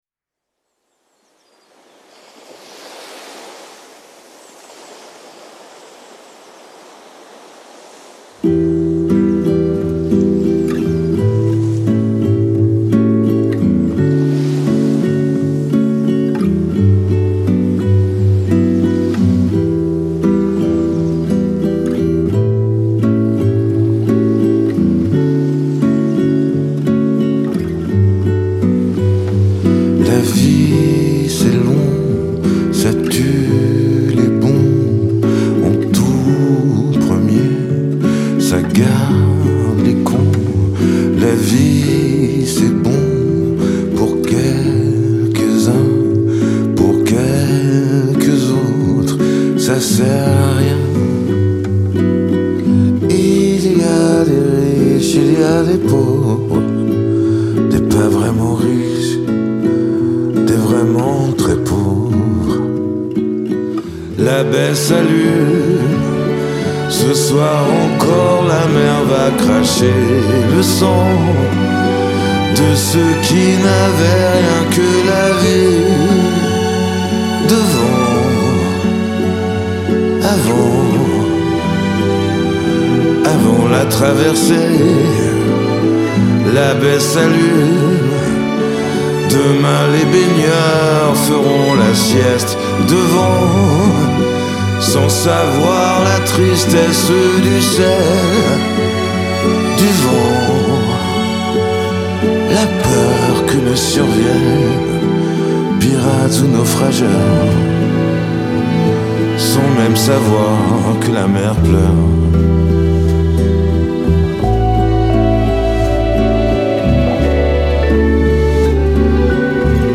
Genre : Chanson française